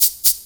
SHAKER LP1-L.wav